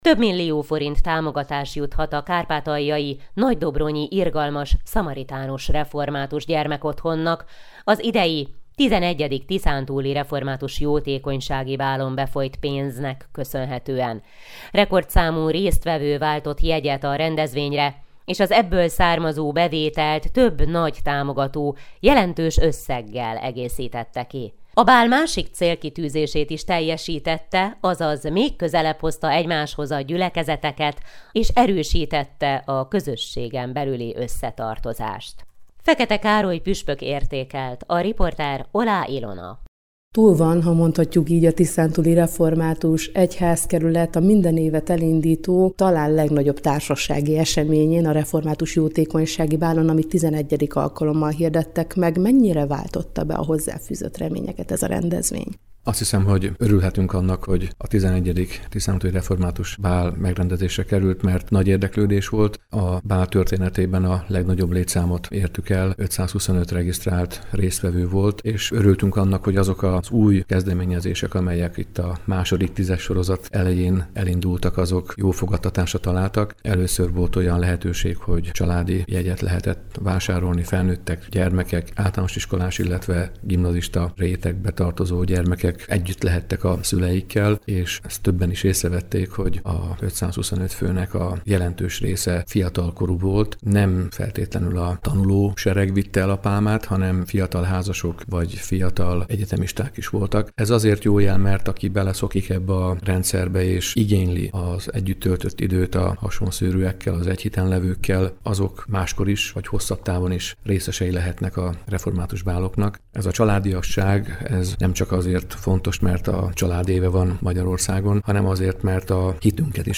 0219-ref-bal-ertekeles-puspok.mp3